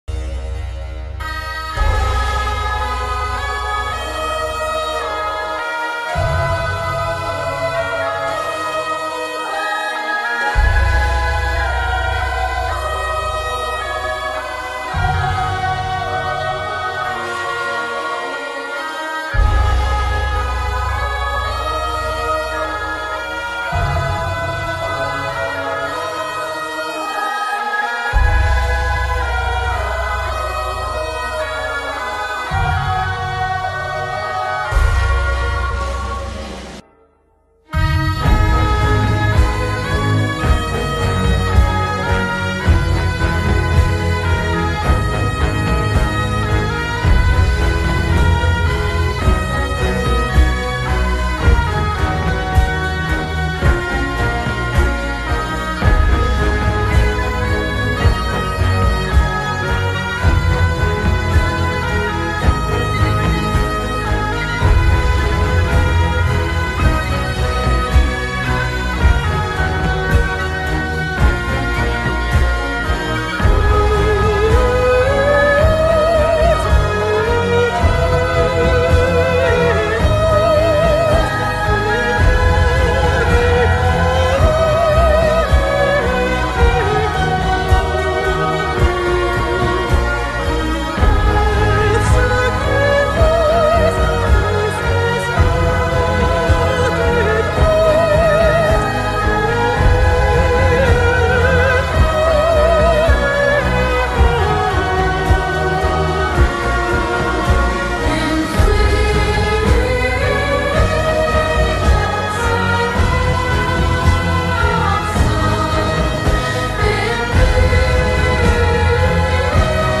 • Качество: высокое